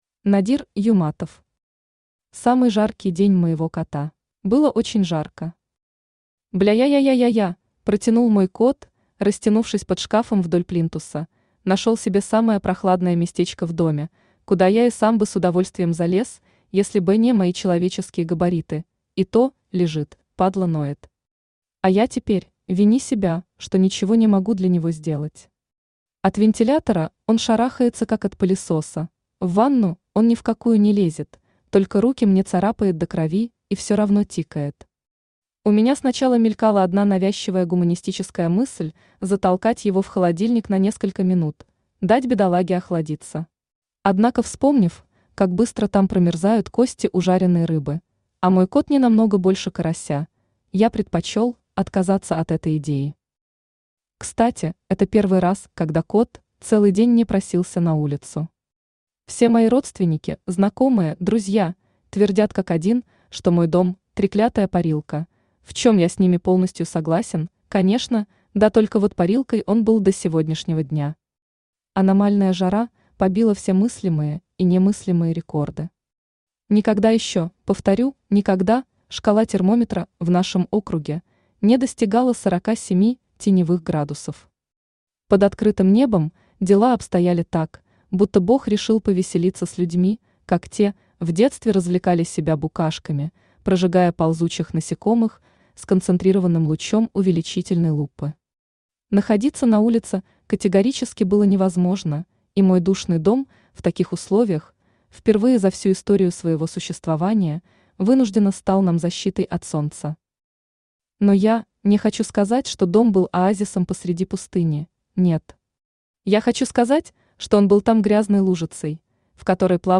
Аудиокнига Самый жаркий день моего кота | Библиотека аудиокниг